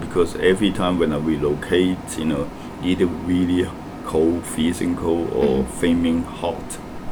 S1 = Hong Kong male S2 = Malaysian female Context : S1 is discussing what he likes about Brunei: the absence of extreme temperatures. S1 : ... because every time when i relocate you know. either really cold freezing cold S2 : mm S1 : or flaming hot Intended Words : flaming Heard as : fuming Discussion : There is no [l] in flaming .